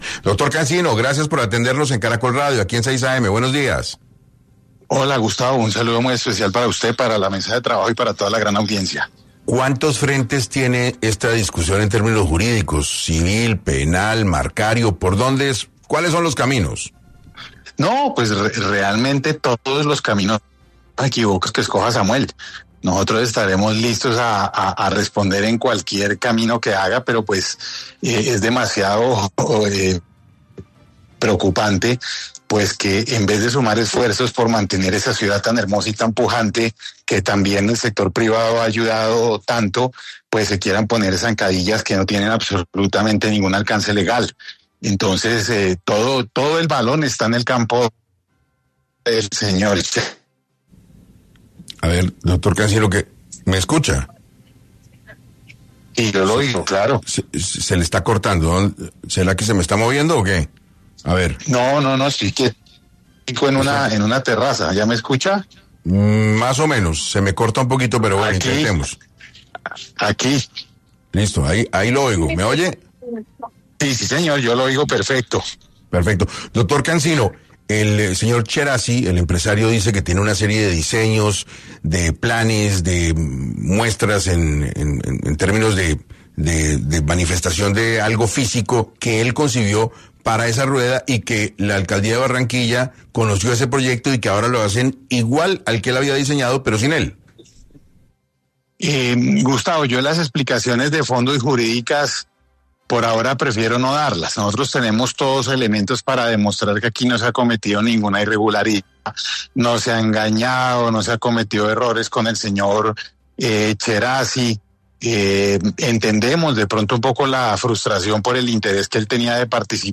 El abogado habló en 6AM de la controversia que se ha generado por la construcción de La luna del Río en Barranquilla.